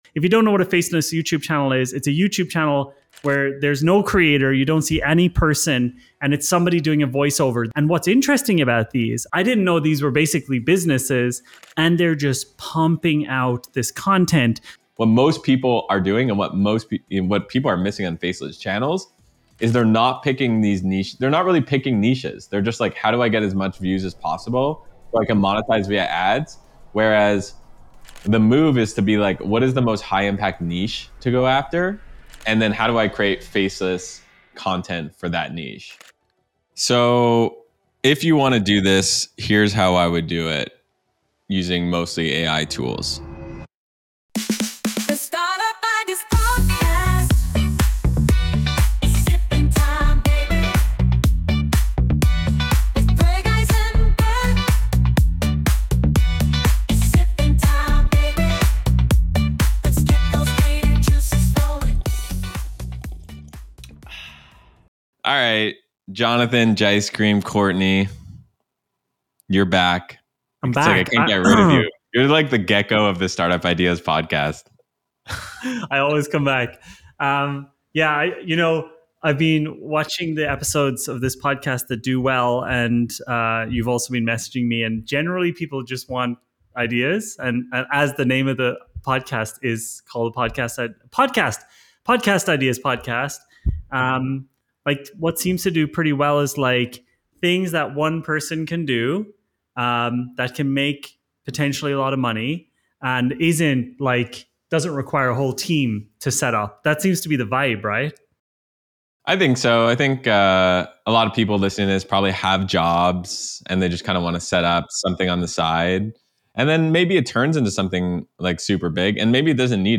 In this discussion